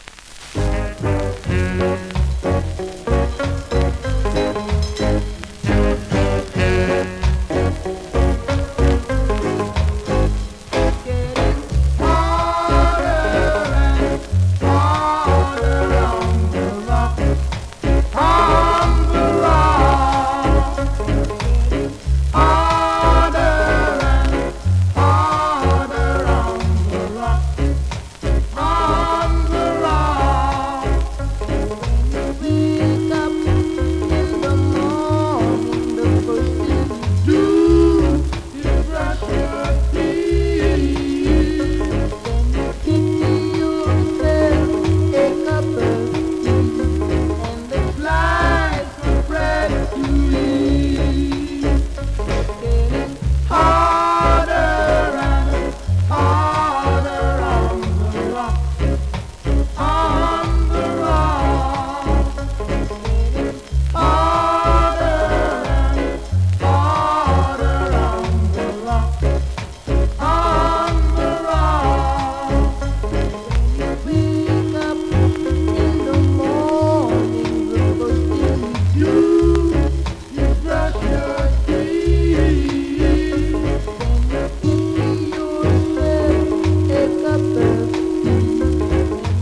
コメントレアJAMAICAプレス!!ROCKSTEADY!!
スリキズ、ノイズそこそこありますが